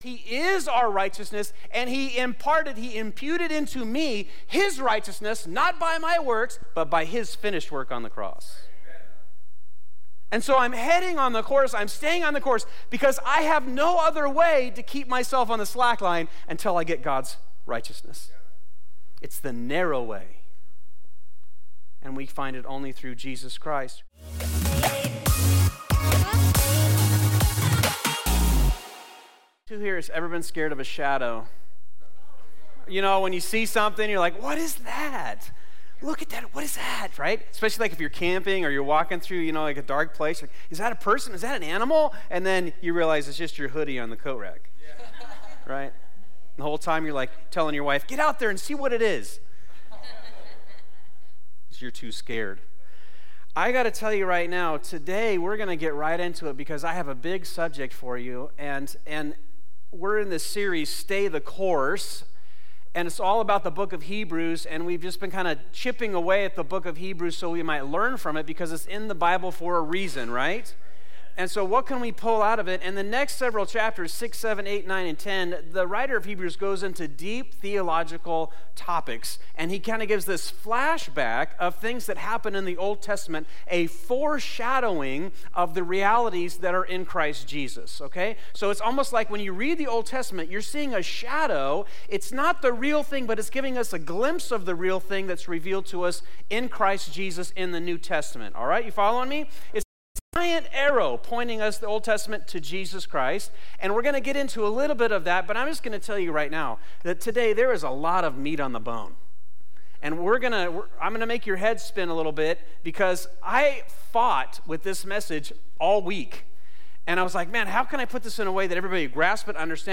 This is part 6 of “Stay the Course,” our sermon series at Fusion Christian Church on the book of Hebrews.